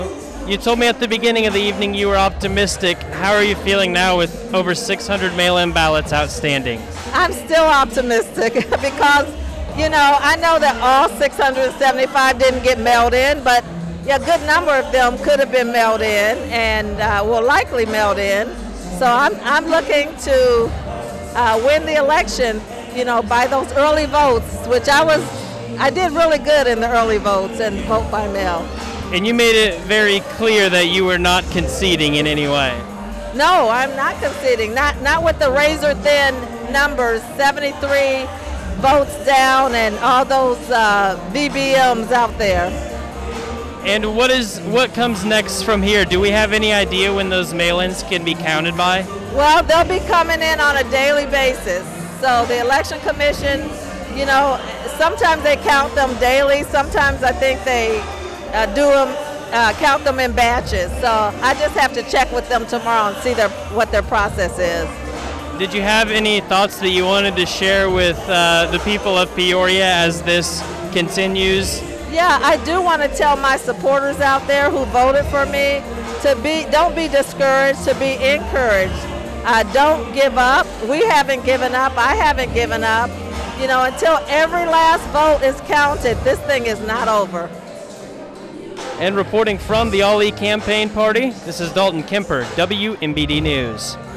Full interview with Ali: